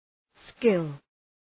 Προφορά
{skıl}